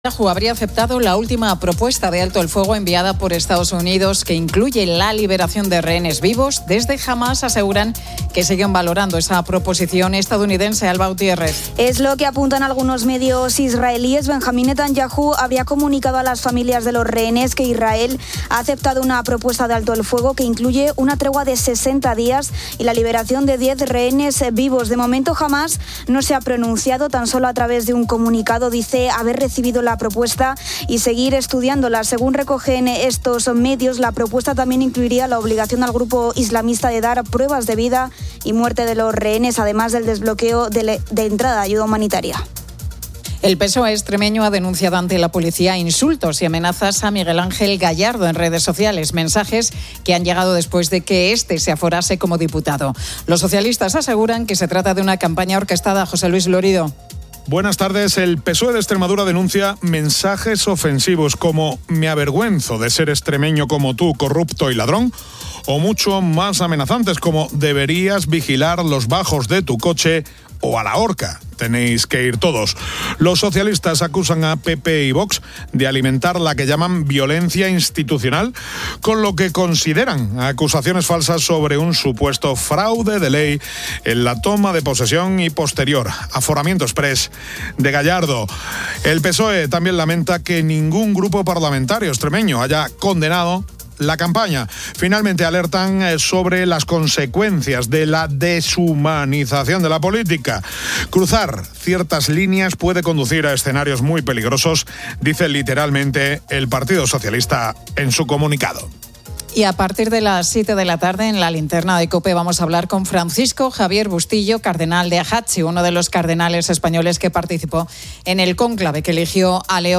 entrevista al actor y director de teatro, Sergi Peris-Mencheta, que presenta su libro "730 días"